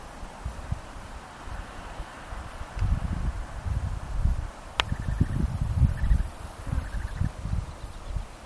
Dot-winged Crake (Laterallus spiloptera)
Contestaban al playback arrimándose bastante pero siempre oculto y sin hacerse una gran despliegue vocal, mucho contacto y estos sonidos intermedios entre un canto y el contacto.
Location or protected area: Punta Piedras
Condition: Wild
Certainty: Observed, Recorded vocal